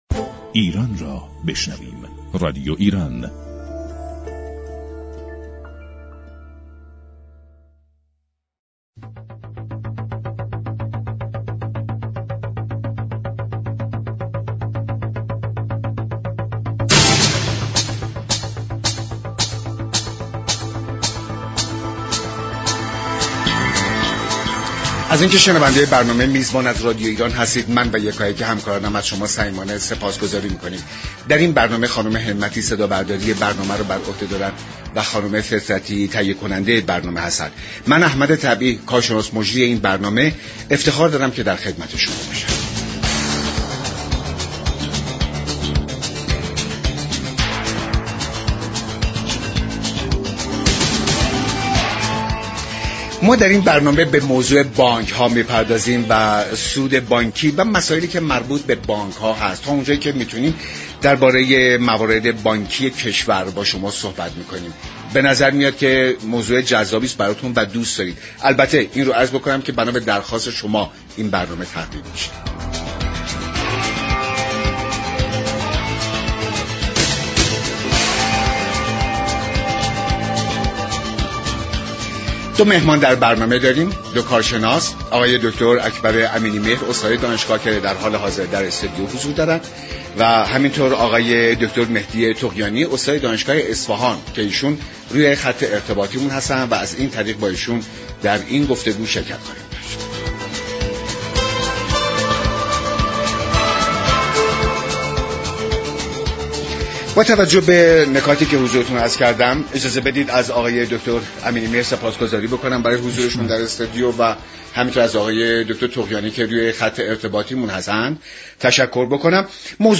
در میزگرد میزبان بحث سود بانكی و نوسانات آن مطرح شد كه كارشناسان این برنامه به تفصیل به آن پاسخ دادند